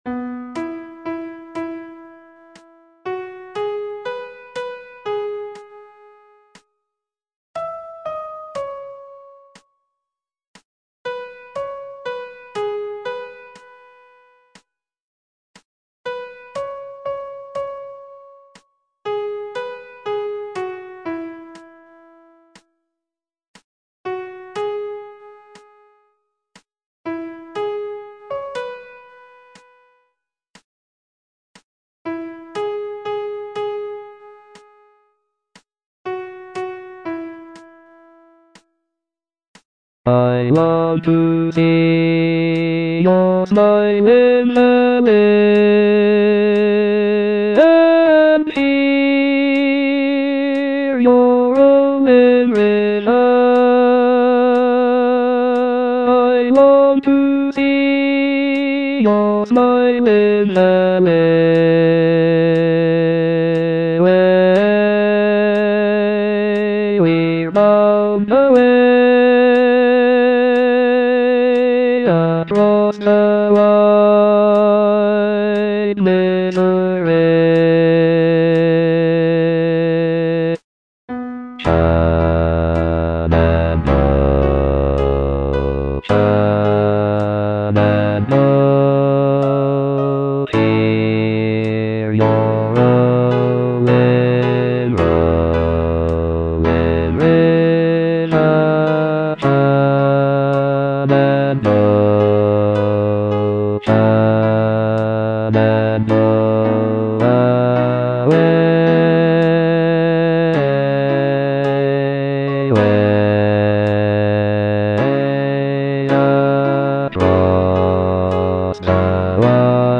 Bass II (Voice with metronome)